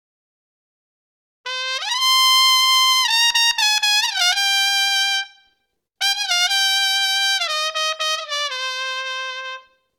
TrumpetLoop01
environmental-sounds-research fanfare latin loop spanish trumpet sound effect free sound royalty free Memes